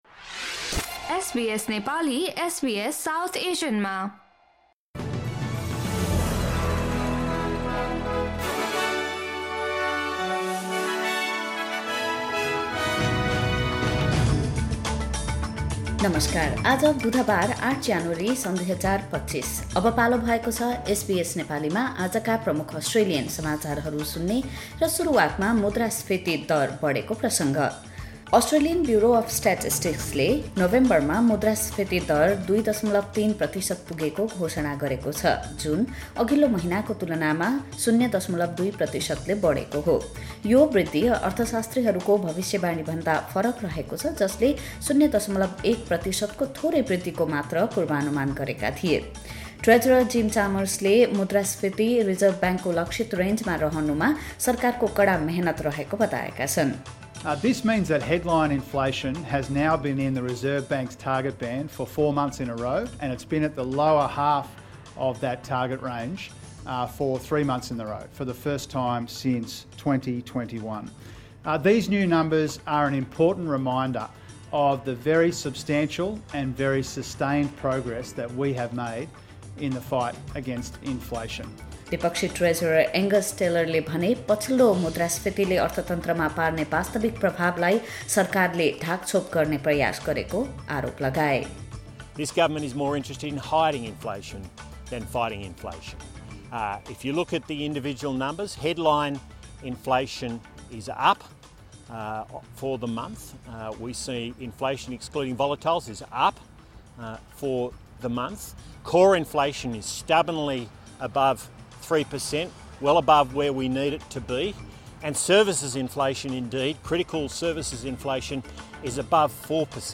SBS Nepali Australian News Headlines: Wednesday, 8 January 2025